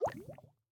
drip_lava_cauldron4.ogg